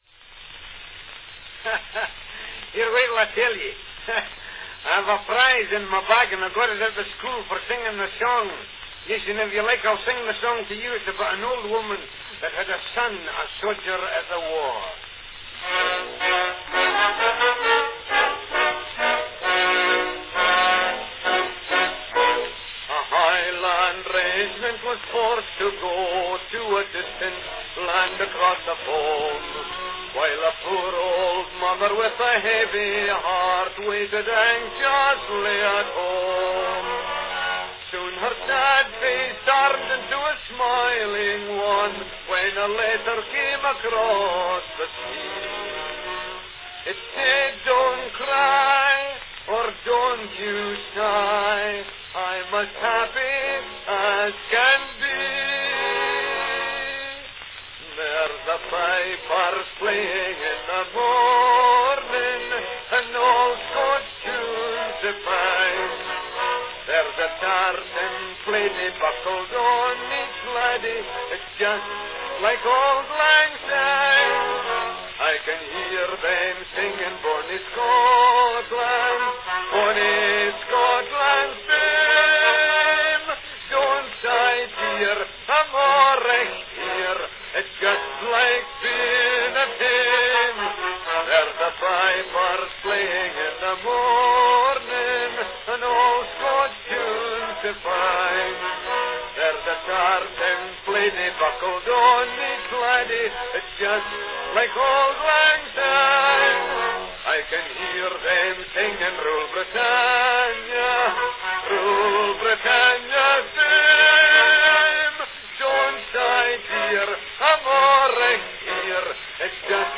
Category Song